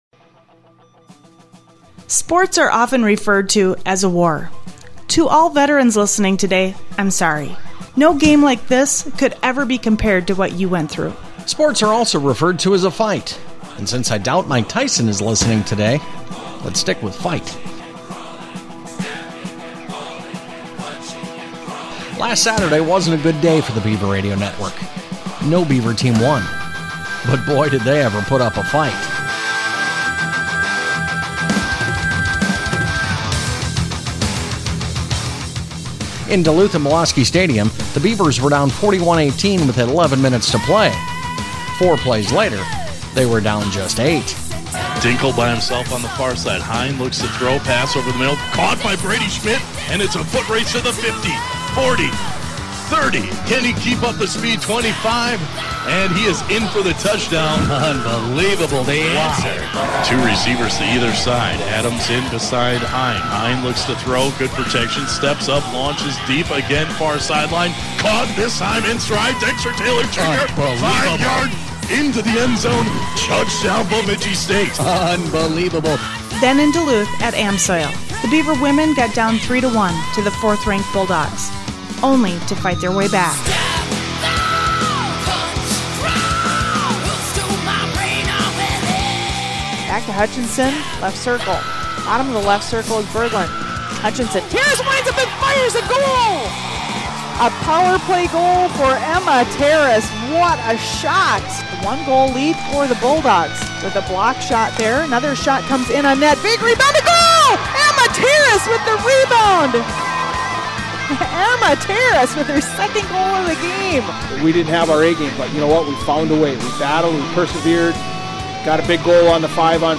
Pregame Open